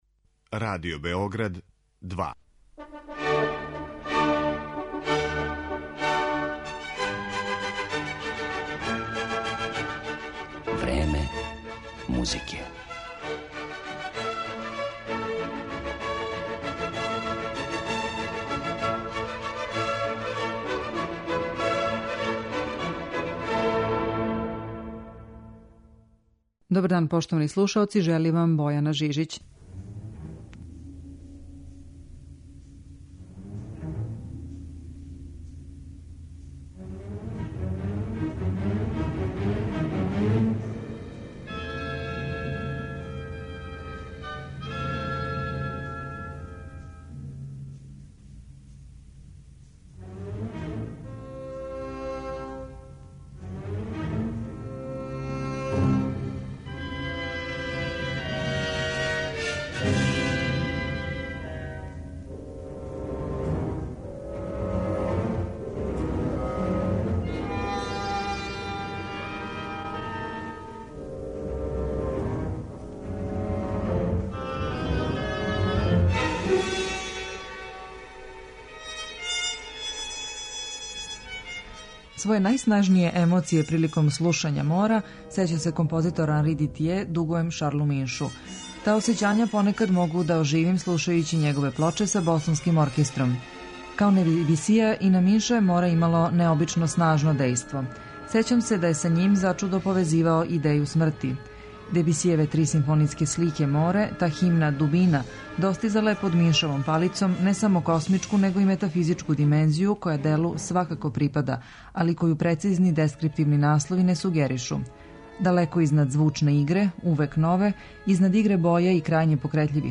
Представићемо га претежно у овом репертоару и у архивским снимцима Радио Београда.